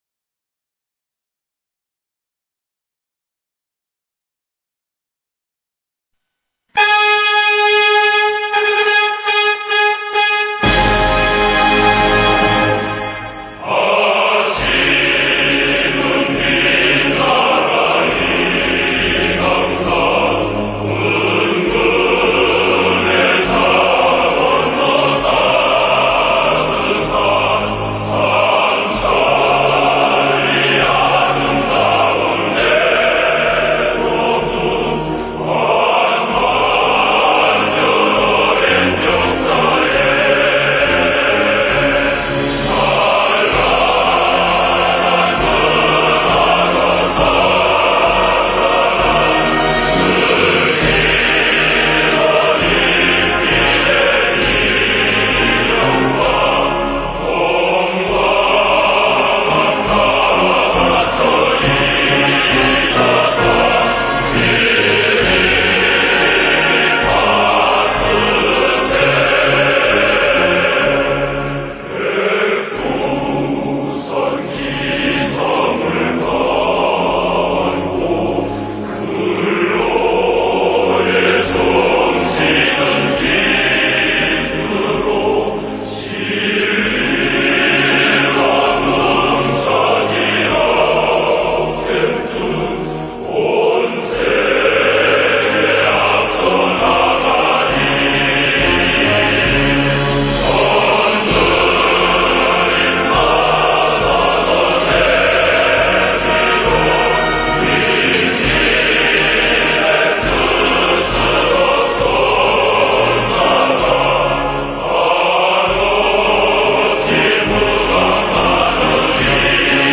朝鲜人民军功勋合唱团版本
朝鲜国歌功勋版本.mp3